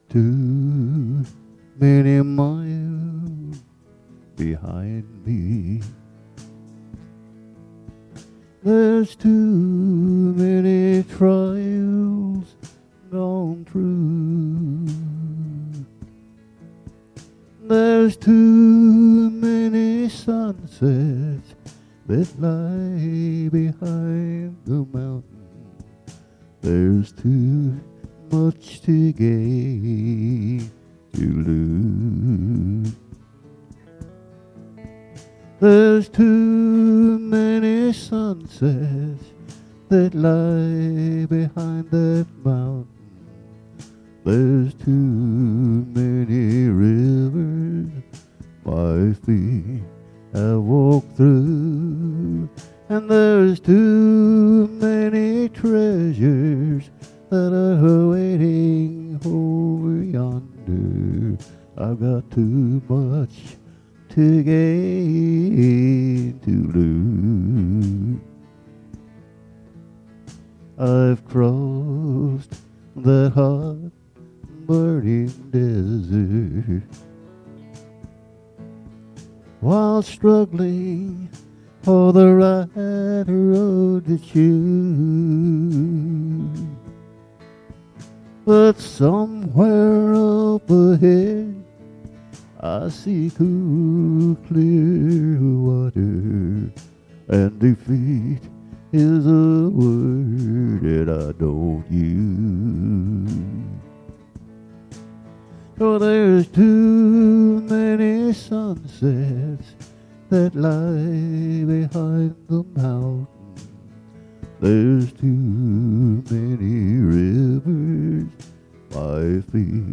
Acts Bible Study 8/14/19